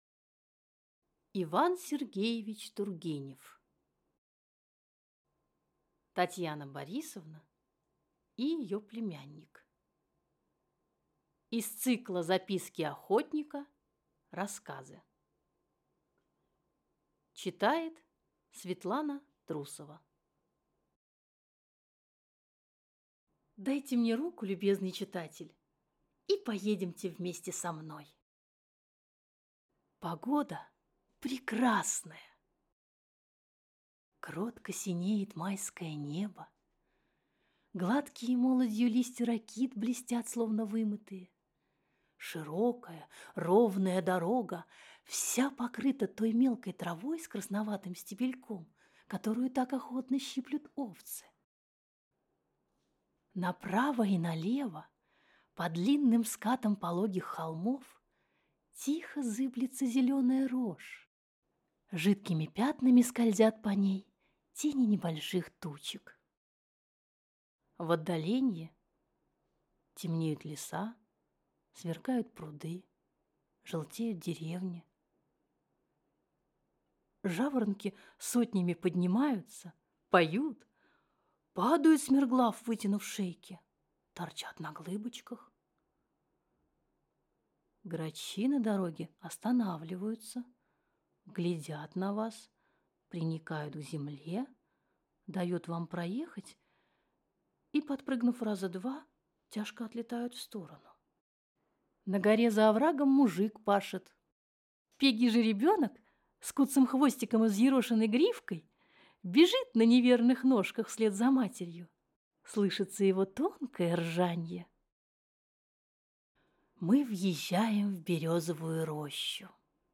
Аудиокнига Татьяна Борисовна и её племянник | Библиотека аудиокниг